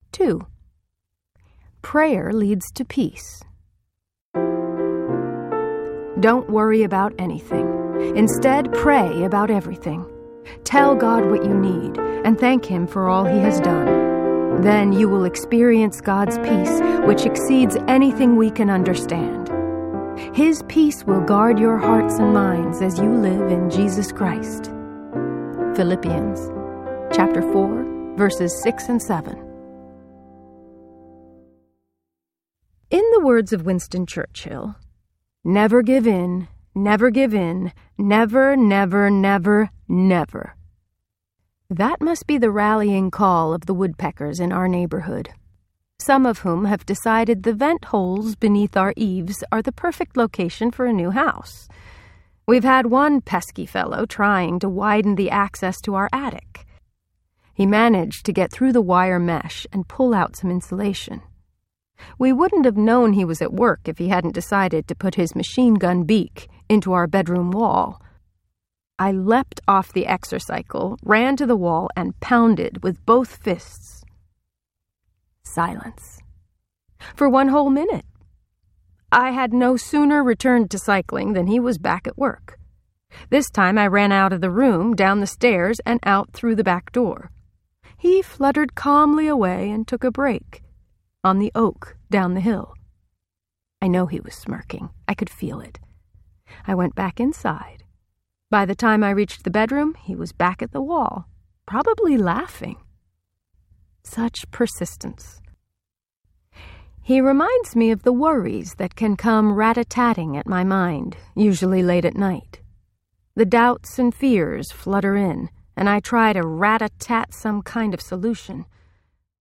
Earth Psalms Audiobook
Narrator